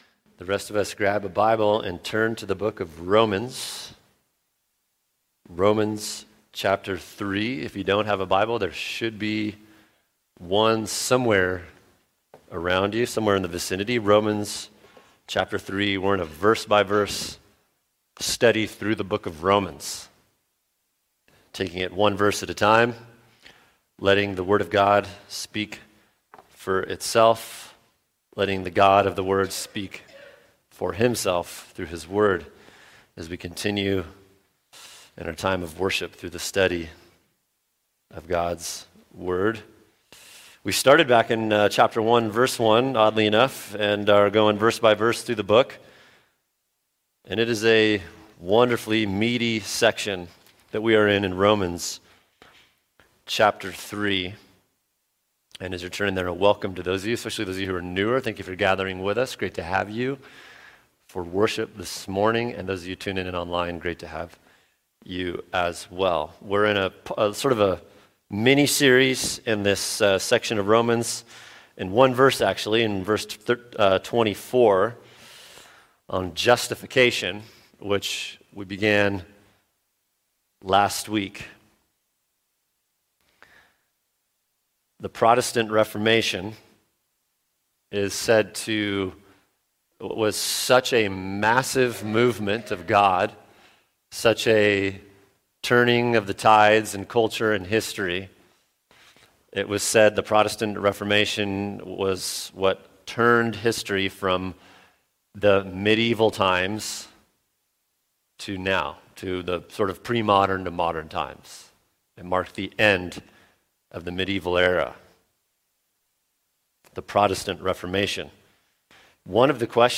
[sermon] Romans 3:24 Justification: Grounds, Timing, Distinct from Sanctification, Implications | Cornerstone Church - Jackson Hole